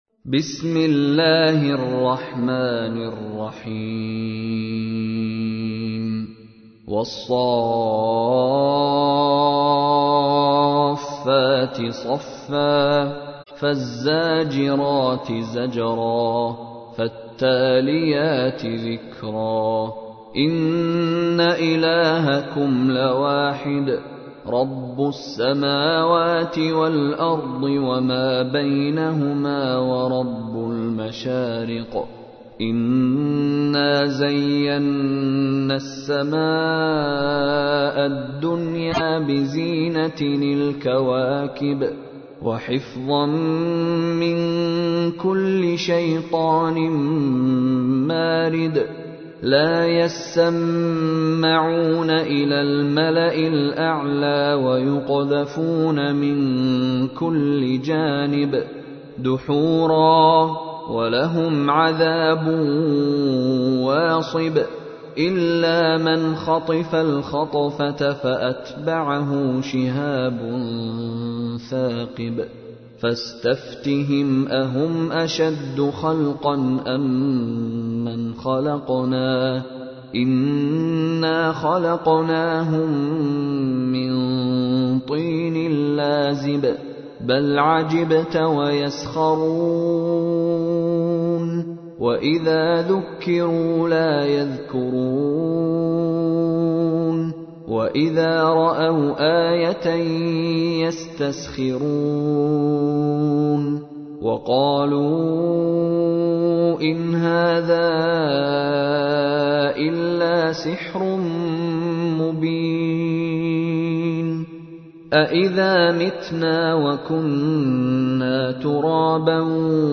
تحميل : 37. سورة الصافات / القارئ مشاري راشد العفاسي / القرآن الكريم / موقع يا حسين